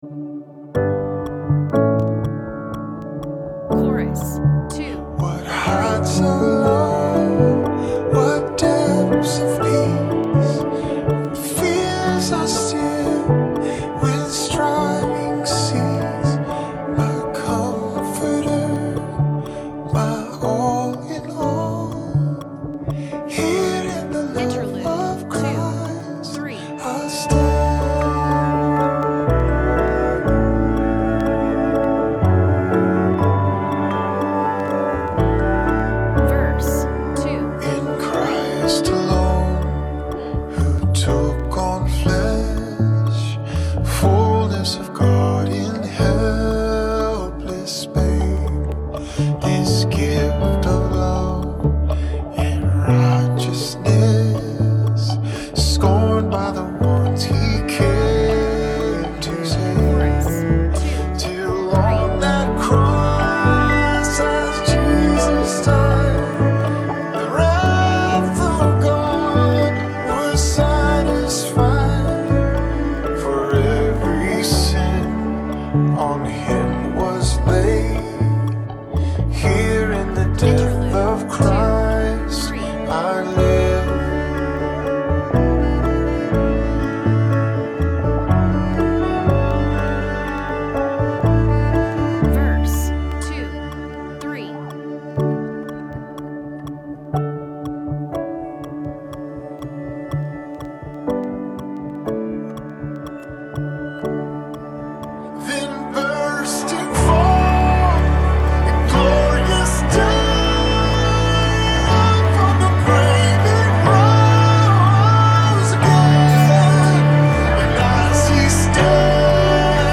Harmony:
In-Christ-Alone-Choir.mp3